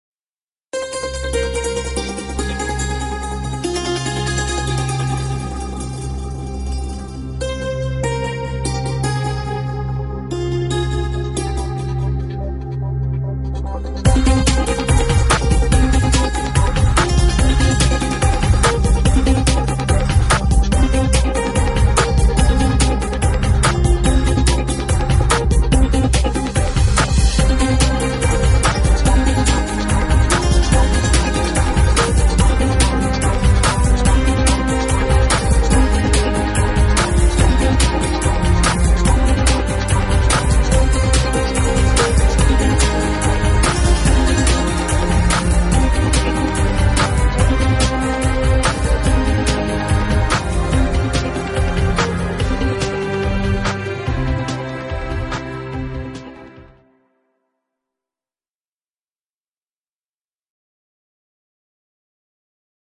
Low Quality